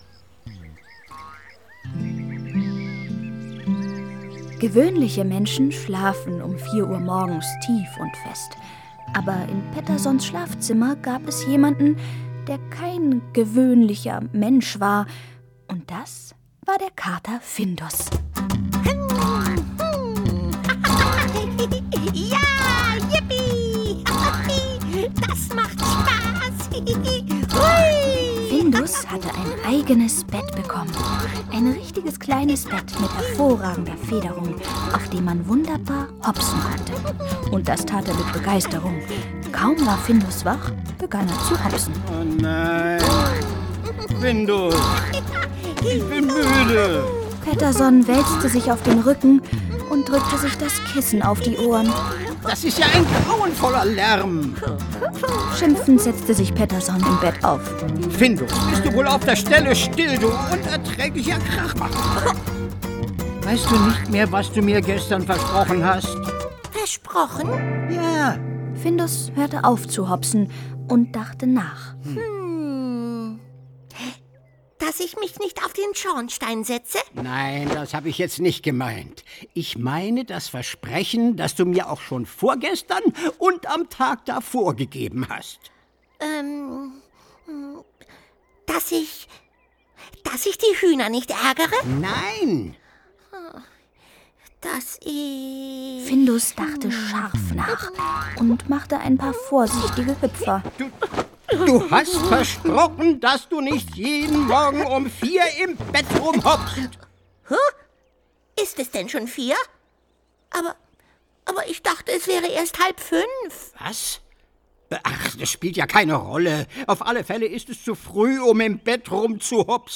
Ravensburger Findus zieht um ✔ tiptoi® Hörbuch ab 4 Jahren ✔ Jetzt online herunterladen!
Findus_zieht_um-Hoerprobe.mp3